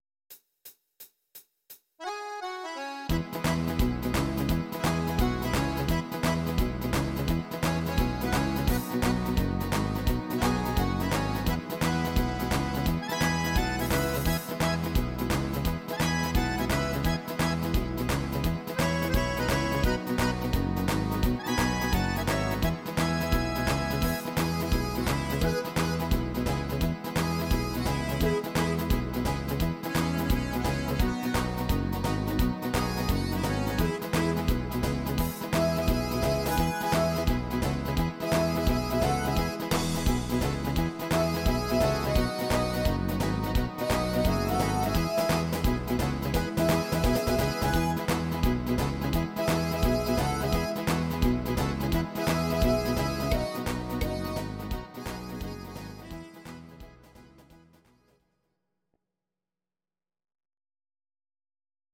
Audio Recordings based on Midi-files
German, 1980s